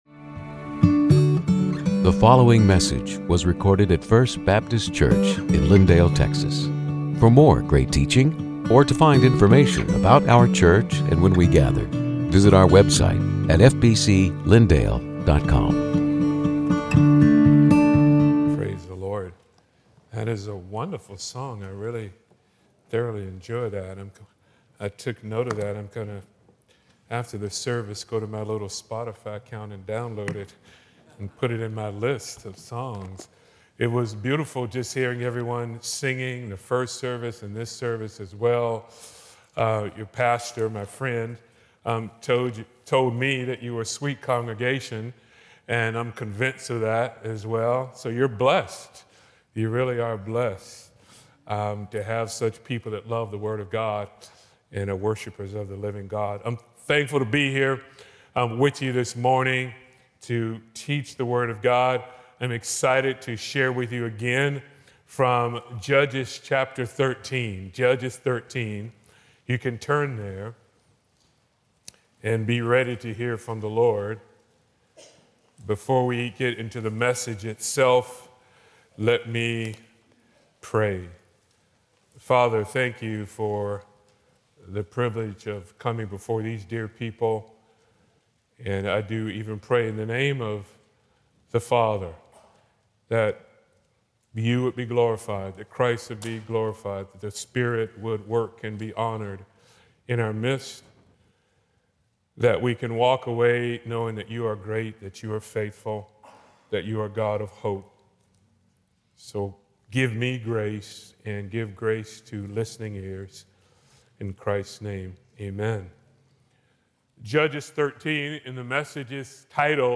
Sermons › Hope In Darkness (Judges 13:8-23)